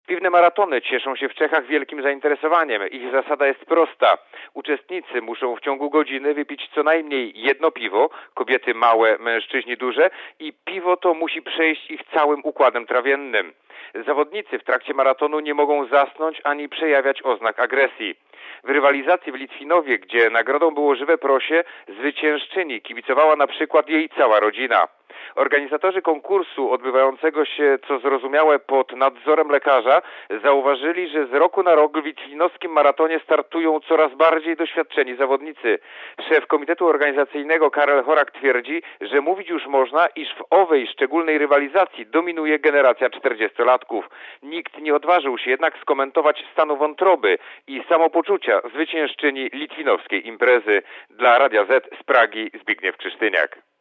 Korespondencja z Czech (420Kb)